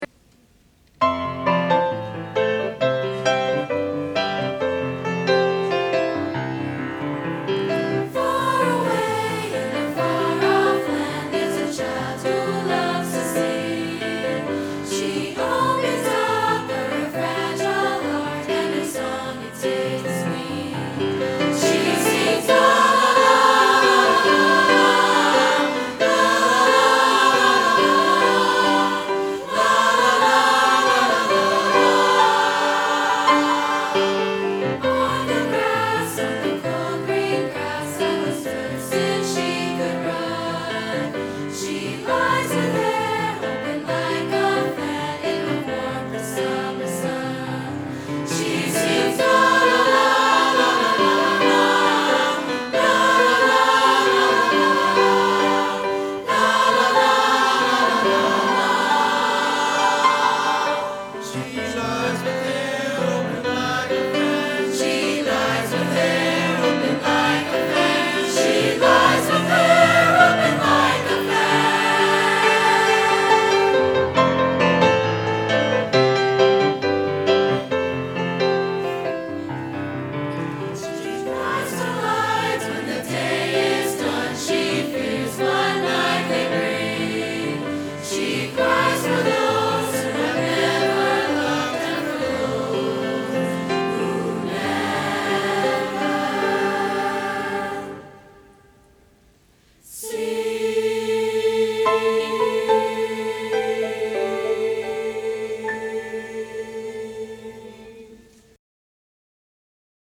2016 Spring Concert - "Songs to Inspire" — The West Covina High School Choral Department
09.)+She+Sings...+(Amy+Feldman+Bernon)+-+Concert+Choir.mp3